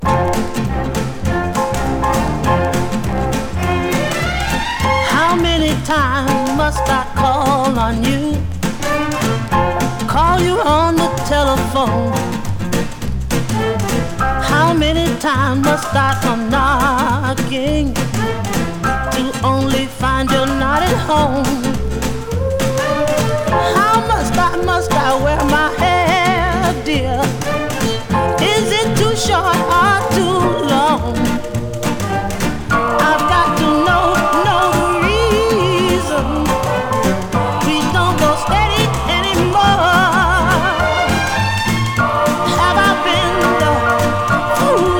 ミッドテンポな楽曲にストリングスやコーラス、力強く、伸びのある魅力溢れるハイトーン・テナーボイス。
R&B, Pop, Vocal　USA　12inchレコード　33rpm　Stereo